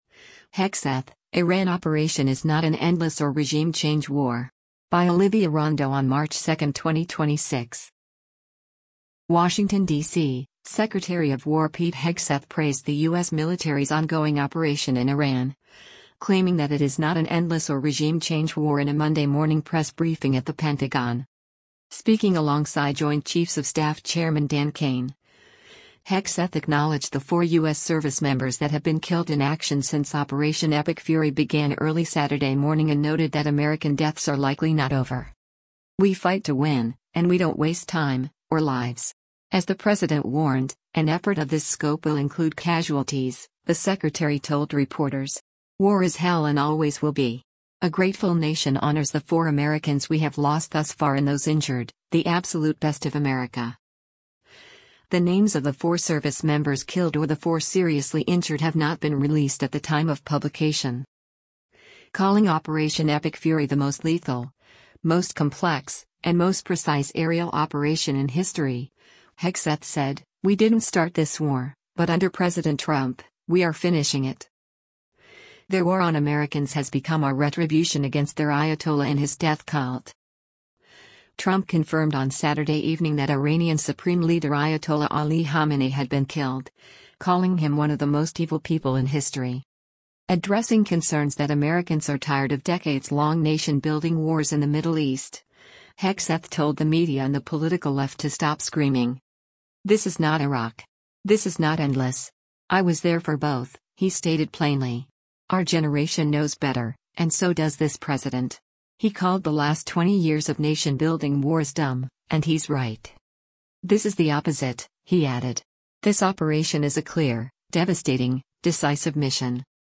Washington, DC — Secretary of War Pete Hegseth praised the U.S. military’s ongoing operation in Iran, claiming that it is not an “endless” or “regime change” war in a Monday morning press briefing at the Pentagon.
Speaking alongside Joint Chiefs of Staff Chairman Dan Caine, Hegseth acknowledged the four U.S. servicemembers that have been killed in action since “Operation Epic Fury” began early Saturday morning and noted that American deaths are likely not over: